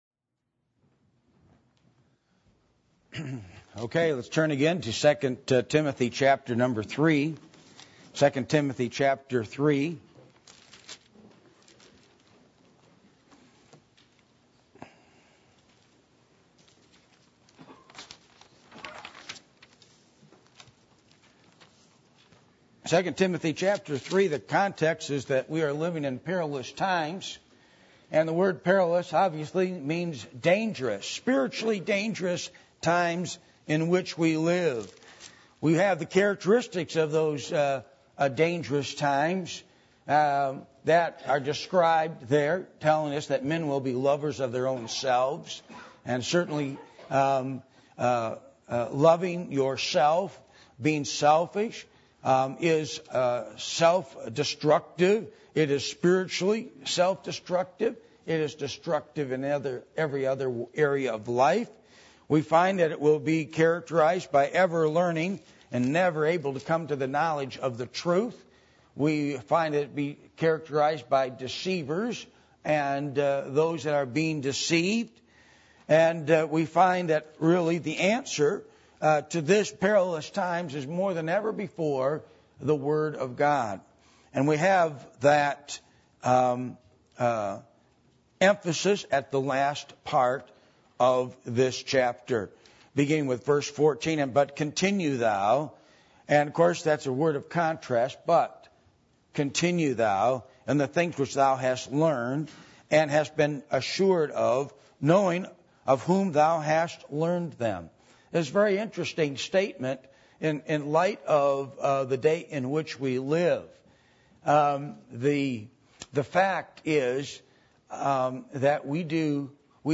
2 Timothy 3:1-17 Service Type: Sunday Morning %todo_render% « Repentance and Salvation